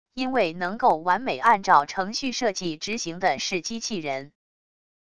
因为能够完美按照程序设计执行的是机器人wav音频生成系统WAV Audio Player